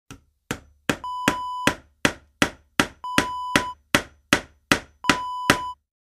Hammering sound effect .wav #1
Description: Hammering nail into wood
Properties: 48.000 kHz 16-bit Stereo
A beep sound is embedded in the audio preview file but it is not present in the high resolution downloadable wav file.
hammering-preview-1.mp3